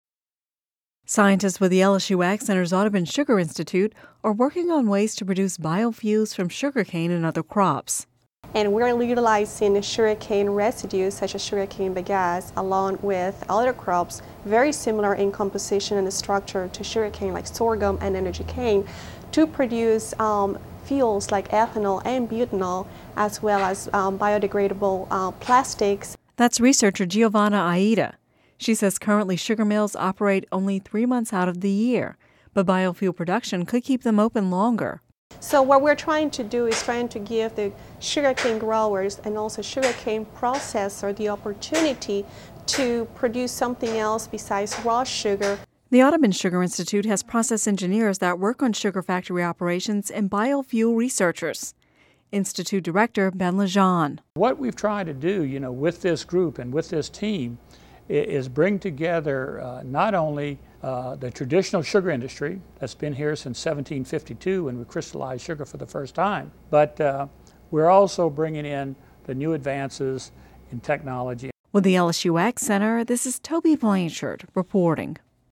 (Radio News 01/03/10) Scientists with the LSU AgCenter’s Audubon Sugar Institute are working on ways to producing biofuels from sugarcane and other crops.